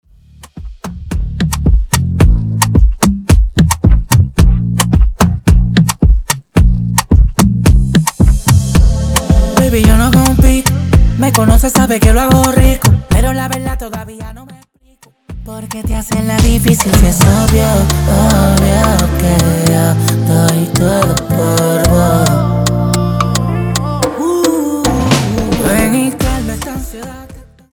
Extended Dirty Intro Acapella